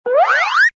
toonbldg_grow.ogg